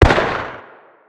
fire_dist.wav